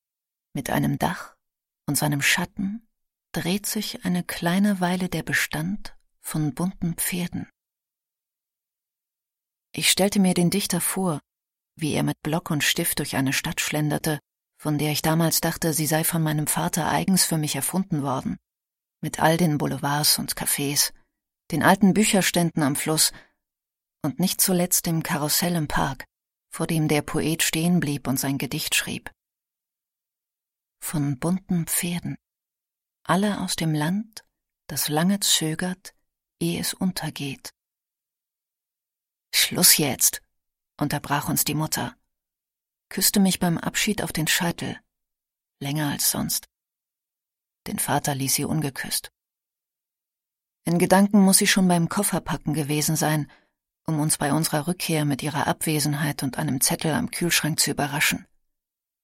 Sprechprobe: Sonstiges (Muttersprache):
german female voice over artist